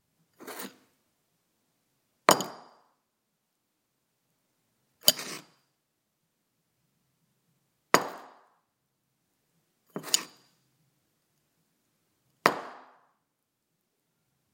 cooking » putting plate1
标签： kitchen table put putting lunch dishes plate
声道立体声